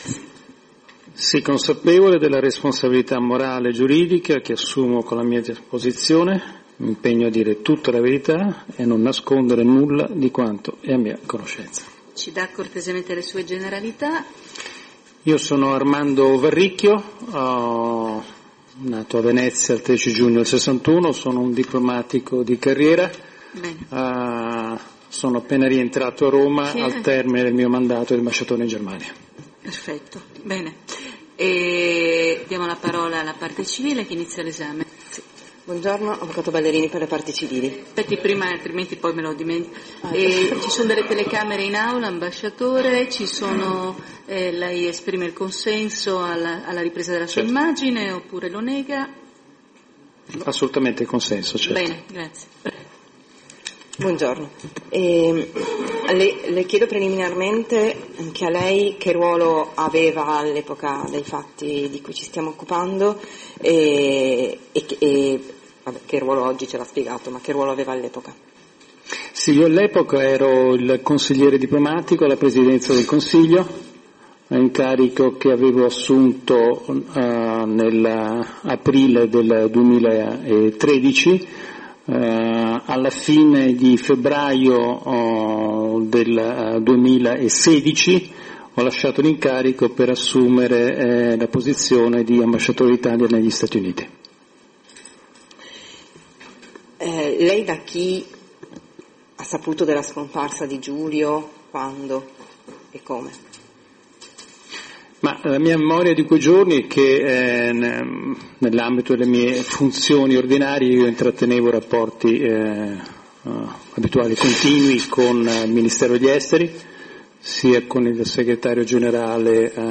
9 anni dopo l'omicidio di Giulio Regeni viene ascoltato Armando Varricchio, ex consigliere diplomatico di Matteo Renzi, come teste al processo di Roma.
Audio (da Radio Radicale) della testimonianza di Armando Varricchio al processo Regeni A me, dopo averne ascoltato l'audio integrale registrato da Radio Radicale, fa nascere una domanda: perché Armando Varricchio è stato ascoltato solo ora dopo 9 anni?